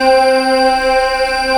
XONIC PAD -L.wav